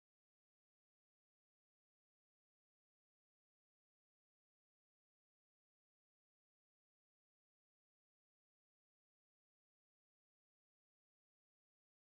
Tonart: F-Dur
Taktart: 2/4
Tonumfang: Oktave